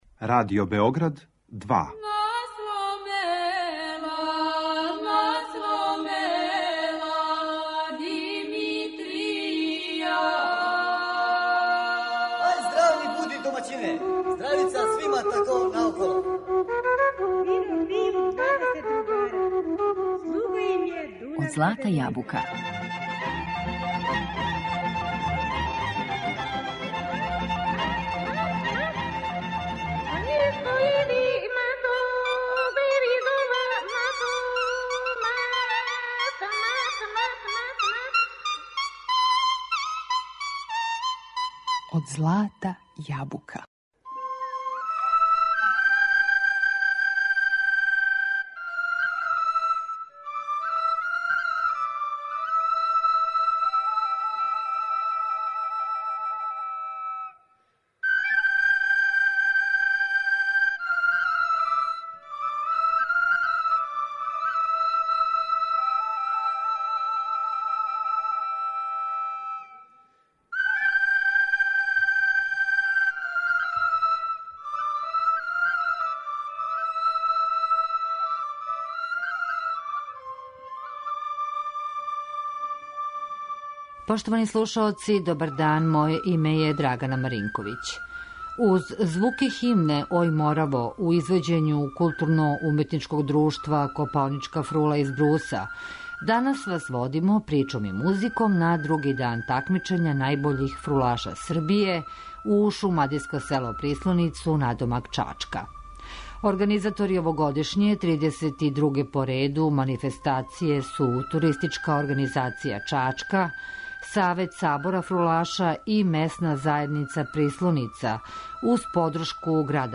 Фрула
Данас вас музиком и причом водимо на место где се овај инструмент негује и чува на најбољи начин, у мало место Прислоницу поред Чачка, на други дан такмичења у савременој категорији најбољих фрулаша Србије.